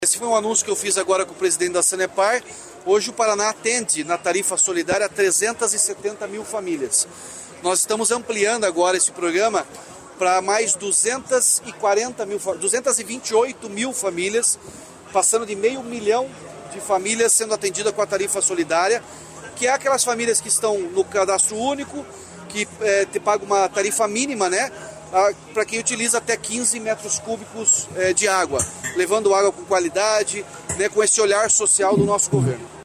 Sonora do governador Ratinho Junior sobre a implantação da Tarifa Social de Água e Esgoto